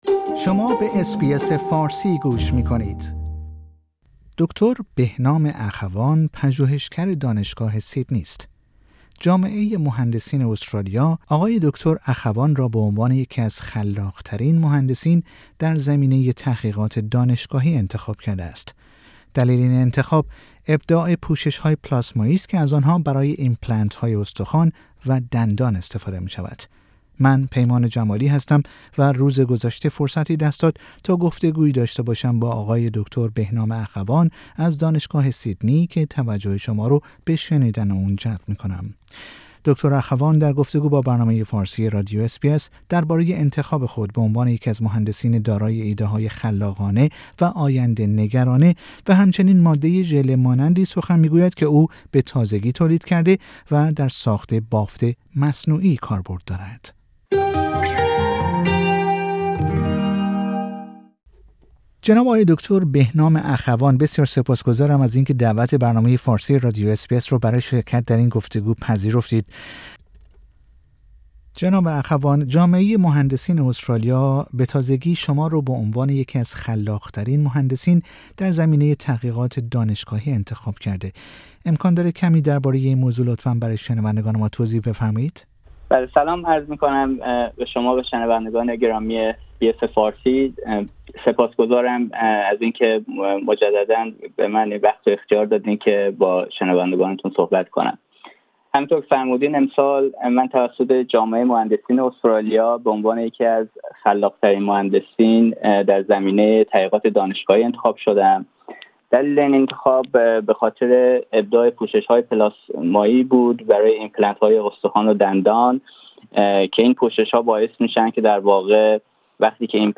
در گفتگو با برنامه فارسی رادیو اس بی اس